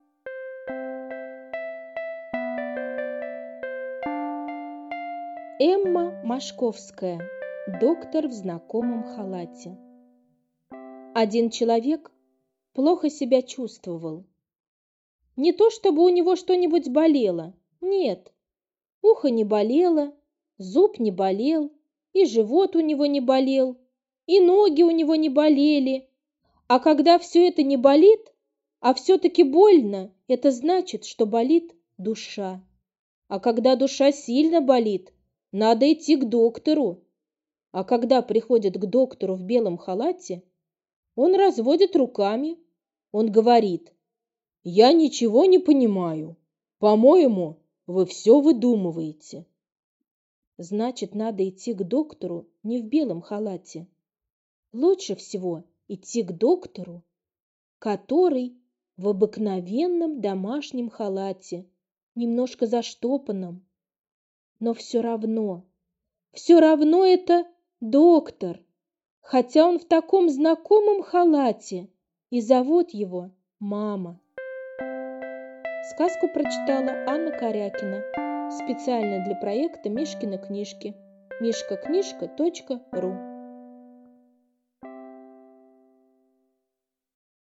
Аудиосказка «Доктор в знакомом халате»